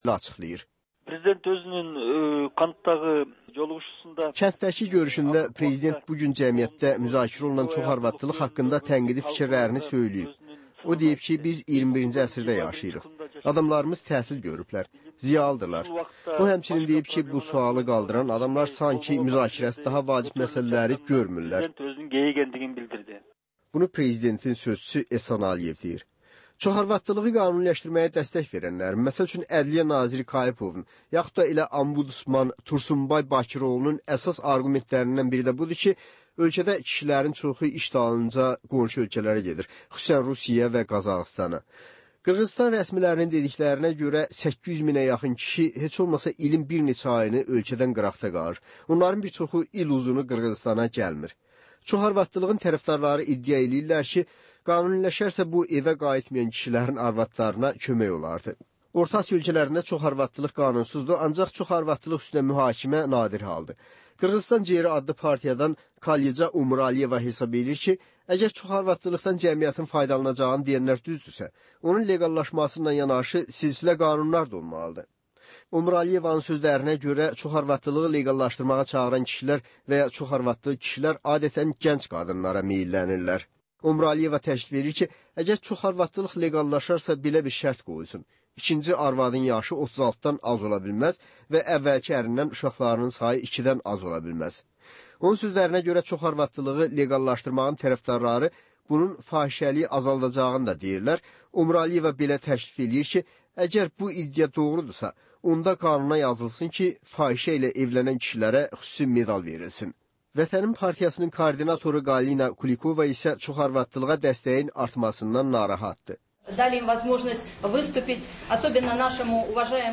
Həftənin aktual məsələsi haqda dəyirmi masa müzakirəsi